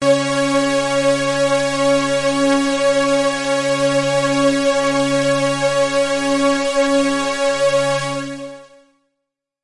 描述：通过Modular Sample从模拟合成器采样的单音。